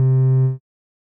bass
添加三个简单乐器采样包并加载（之后用于替换部分音效）